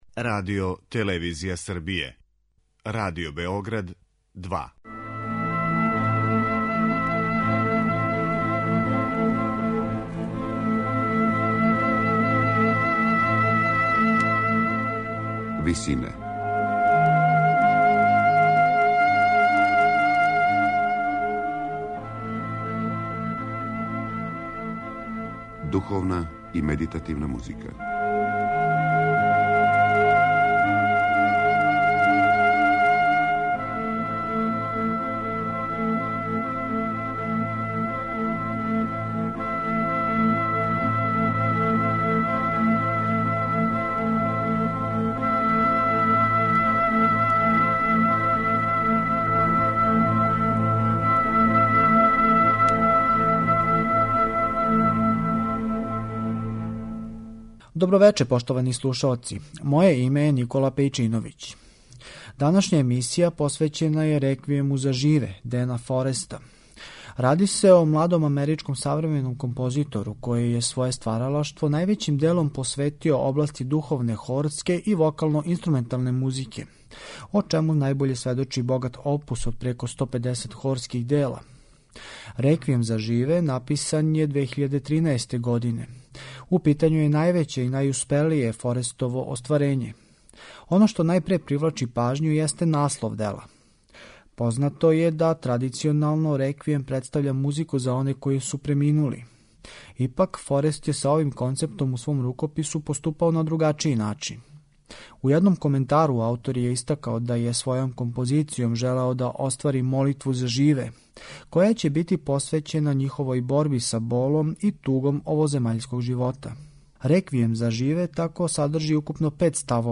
медитативне и духовне композиције
духовној вокално-инструменталној композицији